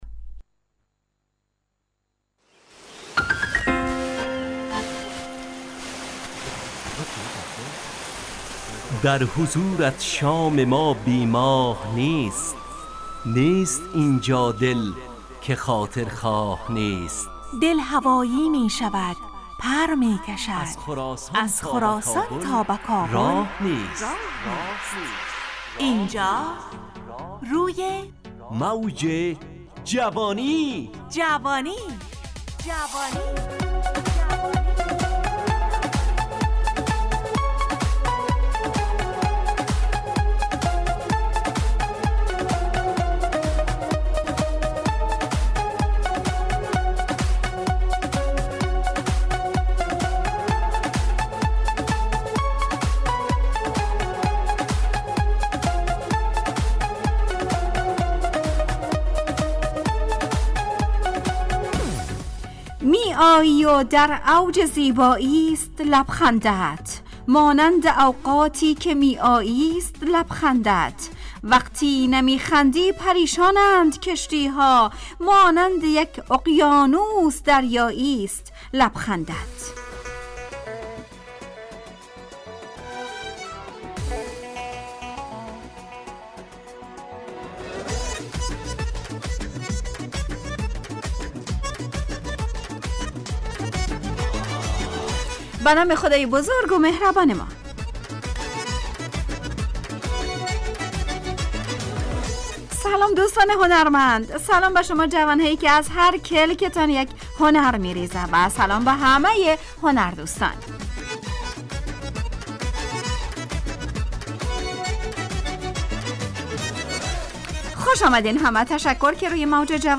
همراه با ترانه و موسیقی مدت برنامه 55 دقیقه . بحث محوری این هفته (هنر) تهیه کننده